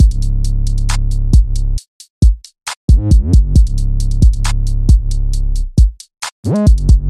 扔掉的Trap鼓与808鼓
Tag: 135 bpm Hip Hop Loops Drum Loops 1.20 MB wav Key : Cm FL Studio